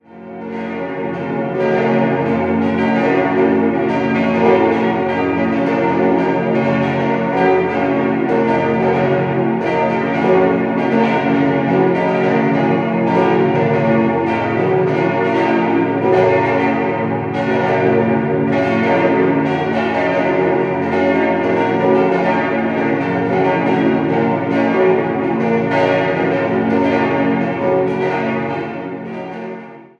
Um 1940 wurde das Gotteshaus verlängert. 6-stimmiges Geläut: ges°-b°-des'-es'-f'-ges' Die Glocken wurden 1940 von der Gießerei Staad gegossen.